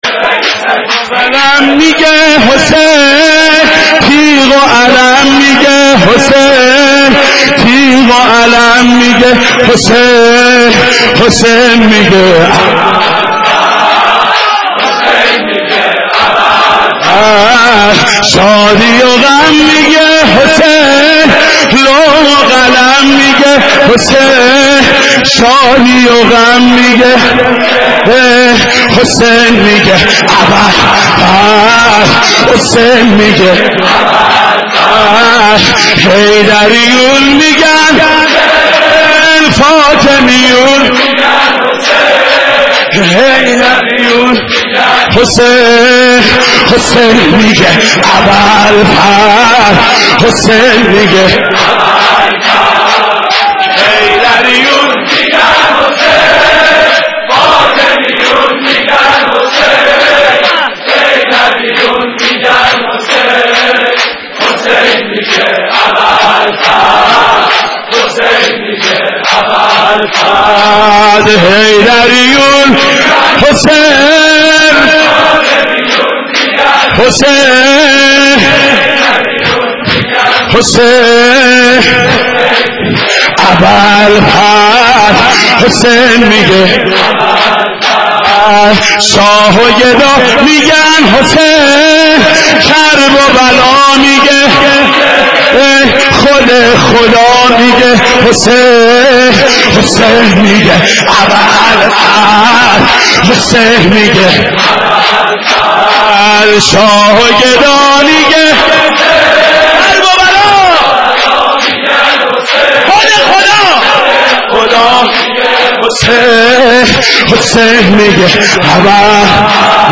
Elegies for Imam Hussein’s Martyrdom(as),Audio